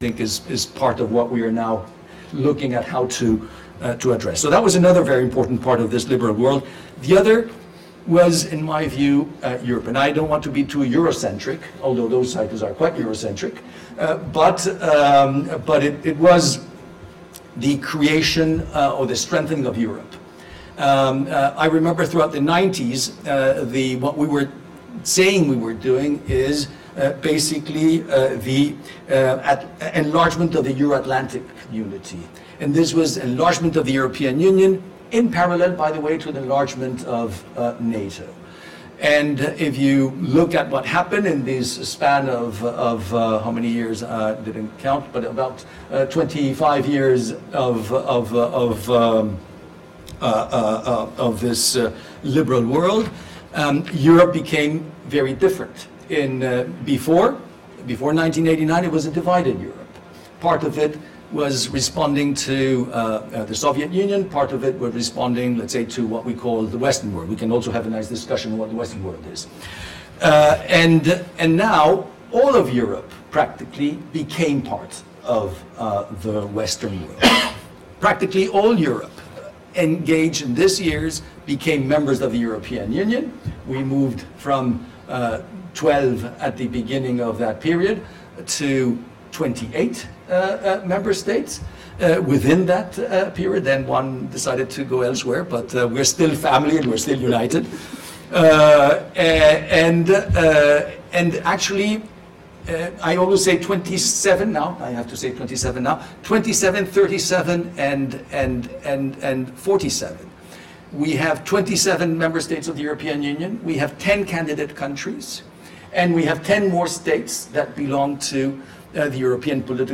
Free public event at LSE
Sign up to mailing list Apple Google Microsoft 365 Outlook Listen to the podcast (due to a technical fault this recording begins 15 mins late) Topics Politics Society Share this page Facebook X Bluesky LinkedIn email